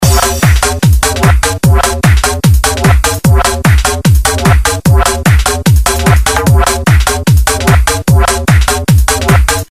hard-bass-1_UegJBid.mp3